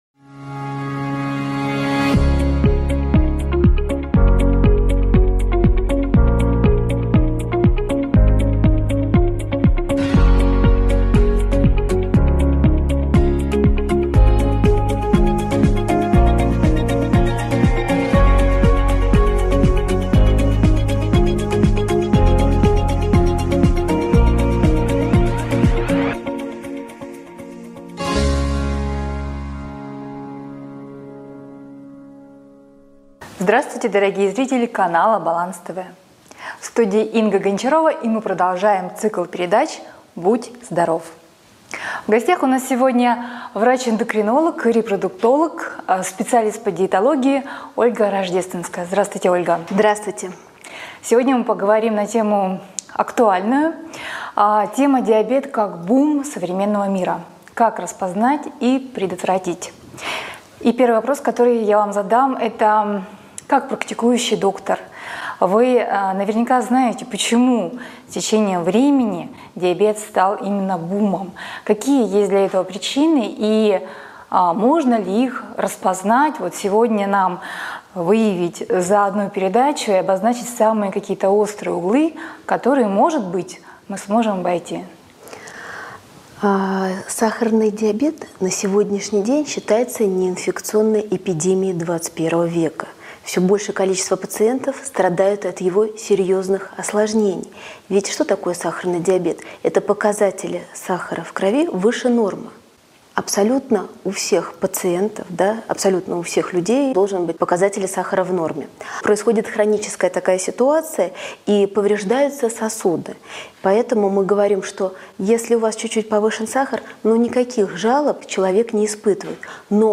Источник: Баланс ТВ